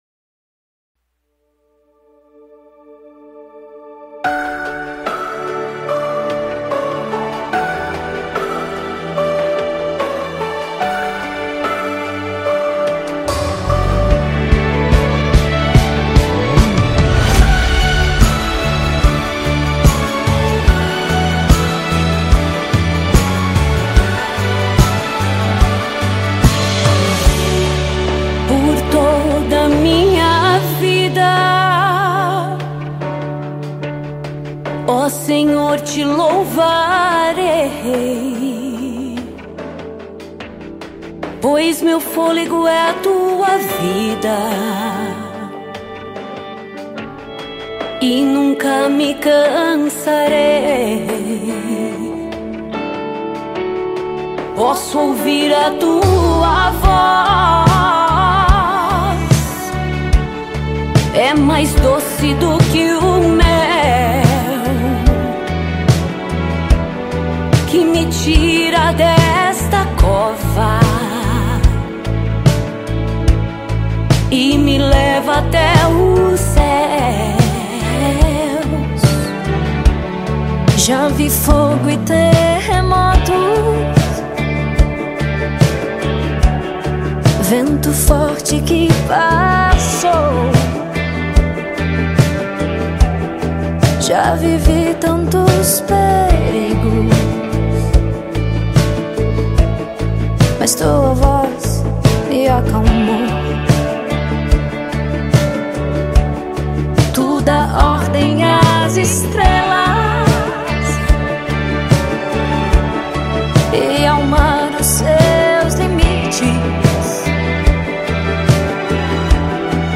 Download Gospel Song